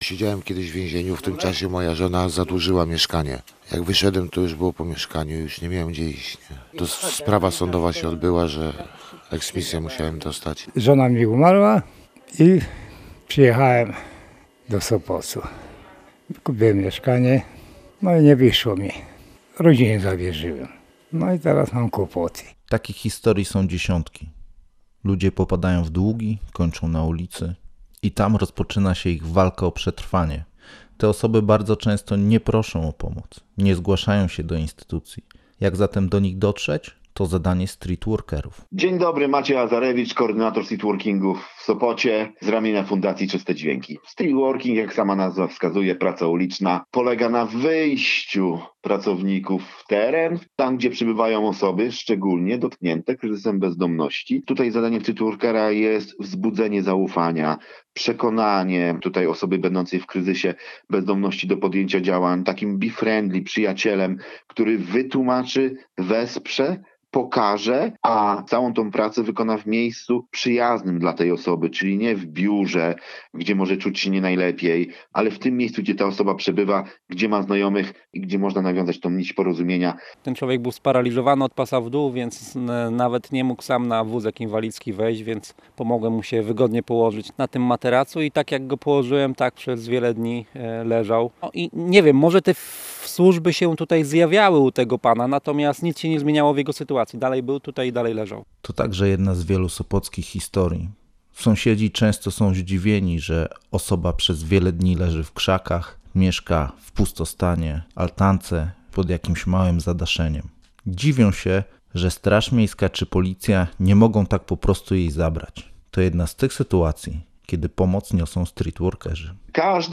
Usłyszeć można także głosy ludzi, którzy z różnych przyczyn stali się bezdomnymi.